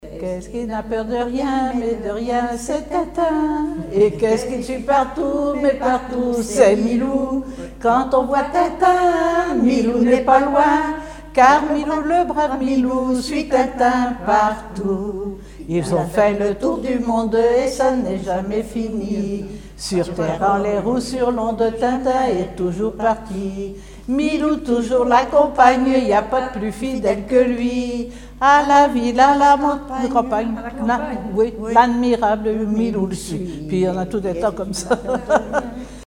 Localisation Île-d'Yeu (L')
Genre brève
Chansons et formulettes enfantines